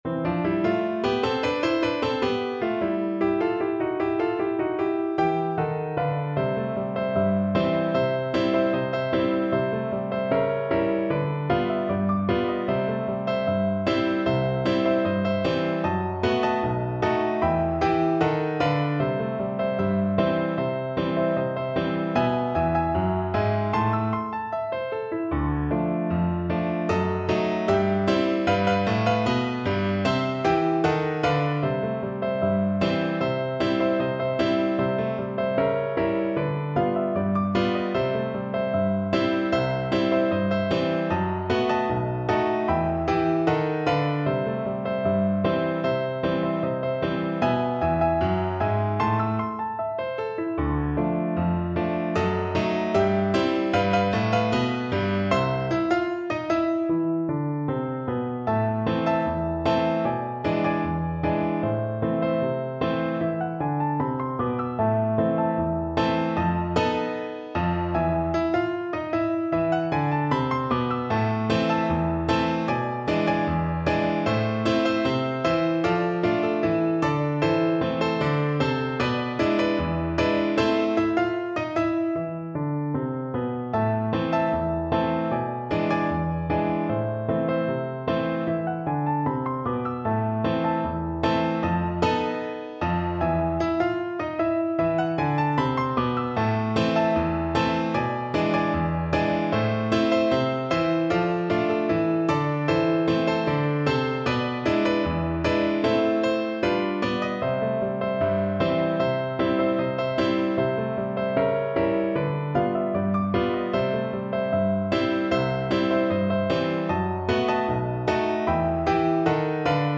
Cello
C major (Sounding Pitch) (View more C major Music for Cello )
Allegro moderato. = 76 Allegro moderato (View more music marked Allegro)
2/4 (View more 2/4 Music)
F3-E5
Jazz (View more Jazz Cello Music)